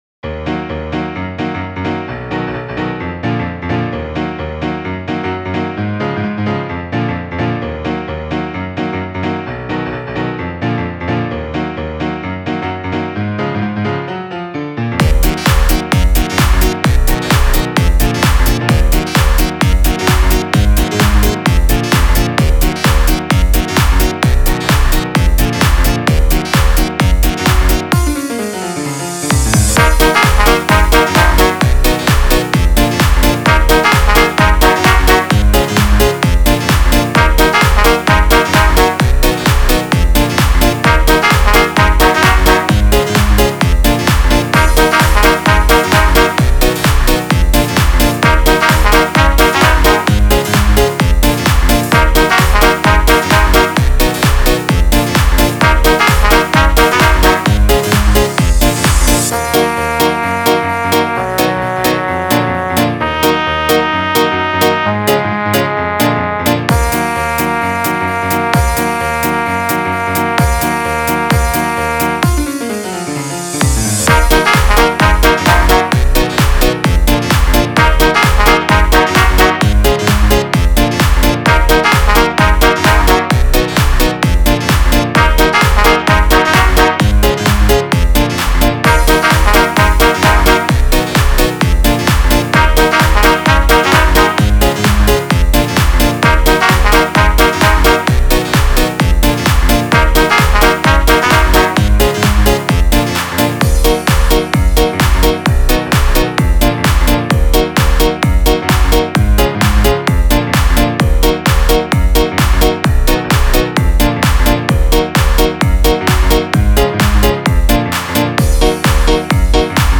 An electro swing interpretation without vocals.
swing.mp3